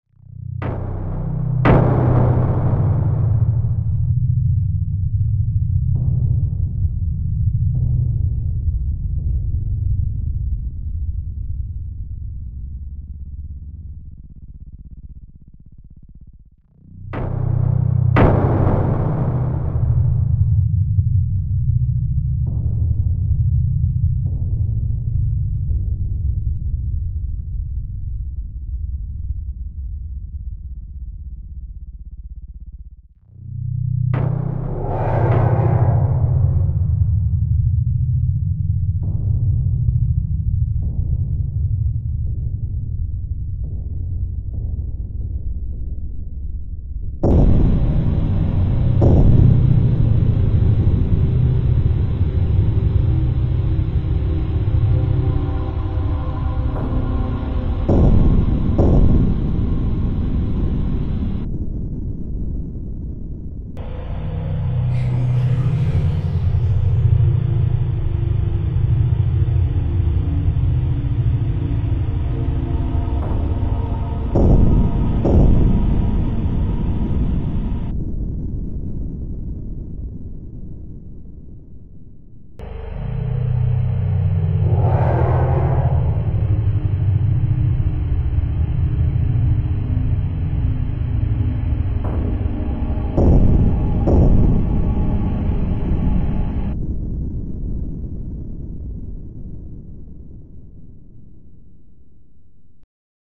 Ambient [MP3]
A piece of creepy, dark ambient. I'm working on using pitchshifted samples to make some creepy atmosphere, the kind of music you want to hear when you patrol a tiberium forest with 2 light infantry and you think you spotted a Tiberium Floater up ahead.
For improvement I'd say make it longer, and add a little melody to it.
Definitely something you'd expect to hear in a video game or movie.
I made it with FruityLoops.